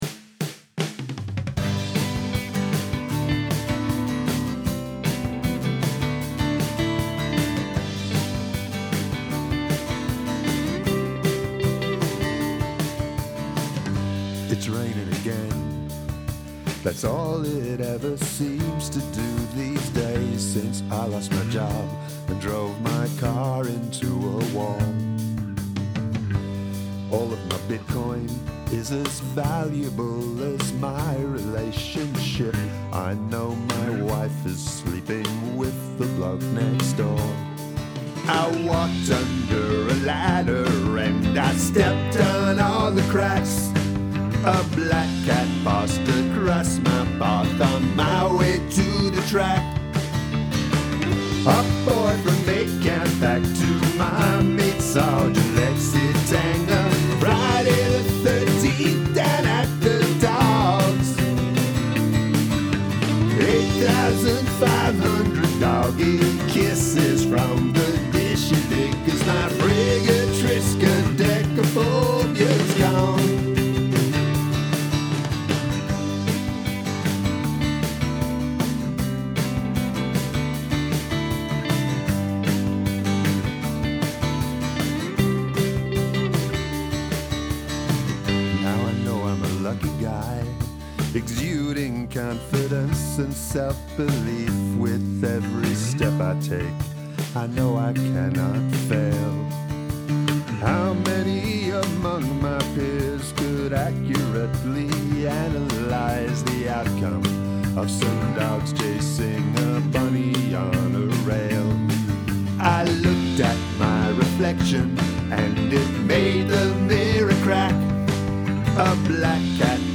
write a feel-good song with happy lyrics and upbeat music
This has one of the best choruses of the round, it's very catchy and I love the rhythm of the vocals.
More minor chords!
I actually like this song more than the majority of the pack here, but aside from a peppy bpm this seems to be drenched in melancholia. I like when the trumpets come in near the end.